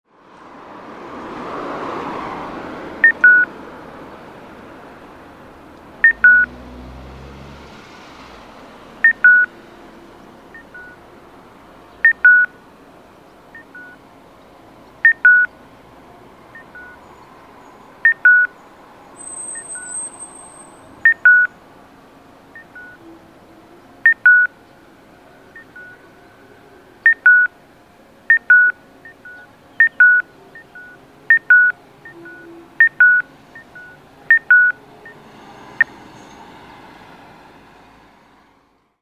交通信号オンライン｜音響信号を録る旅｜大分県の音響信号｜[別府:0036]東別府・山家入口
東別府・山家入口(大分県別府市)の音響信号を紹介しています。